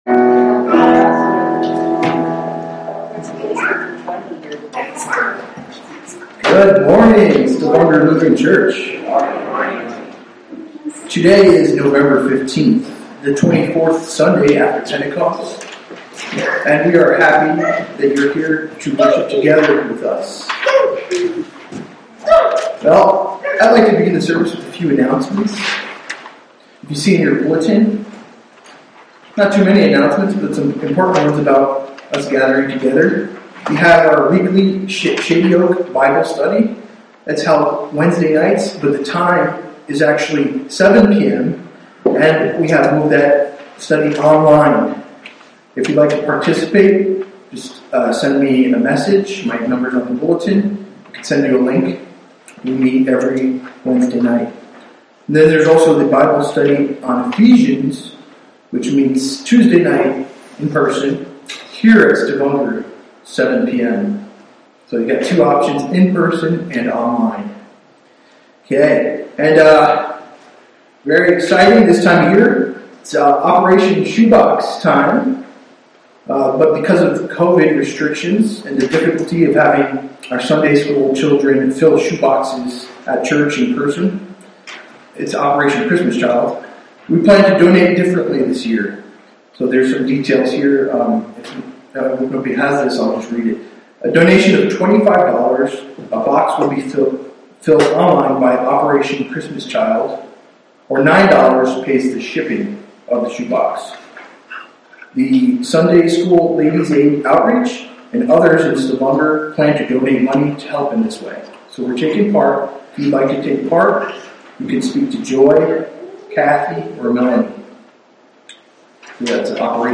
Sermons - Stavanger Lutheran Church
From Series: "Sunday Worship"